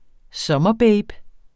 Udtale [ ˈbεjb ]